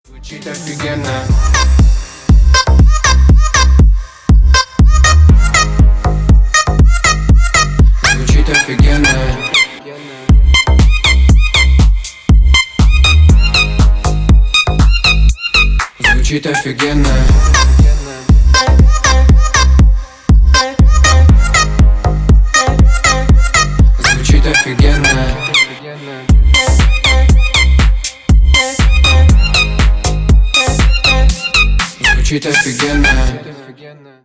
• Качество: Хорошее
• Песня: Рингтон, нарезка
• Категория: Русские рингтоны